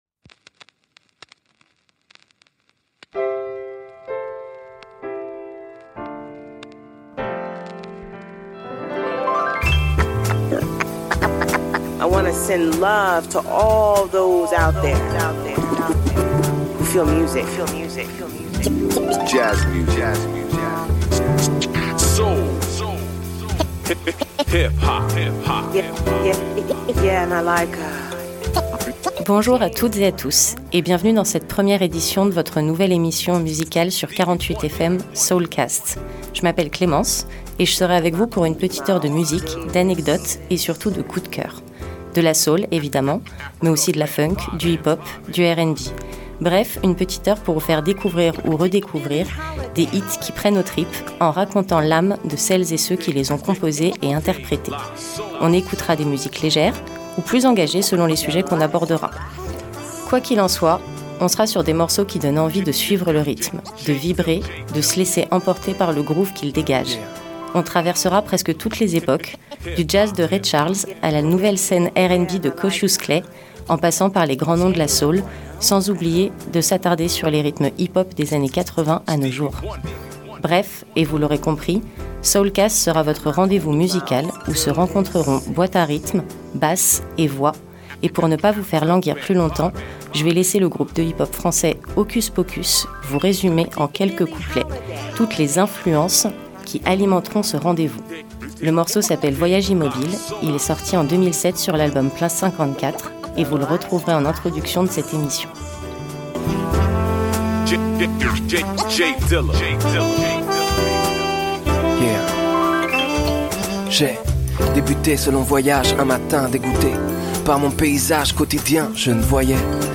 sonorités et voix rnb, soul, hiphop